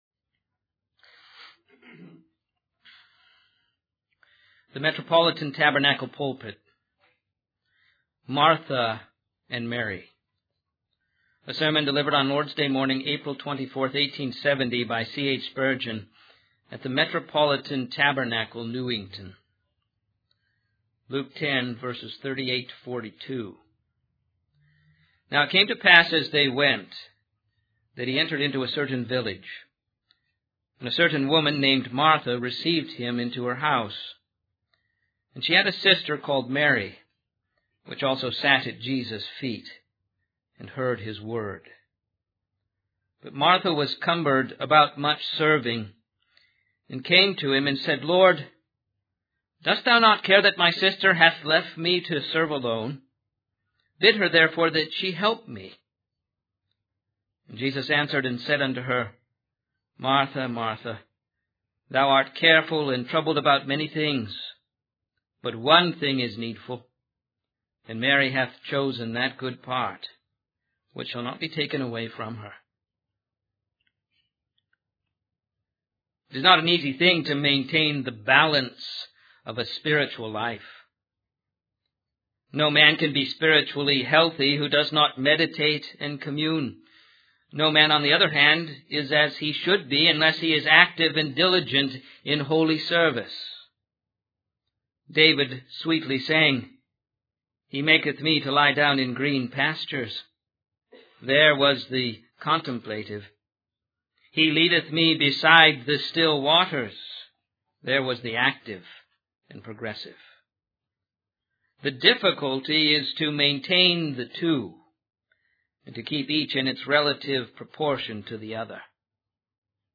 In this sermon, the preacher emphasizes the importance of having a strong inner life and a deep connection with God. He uses the example of Mary and Martha to illustrate this point.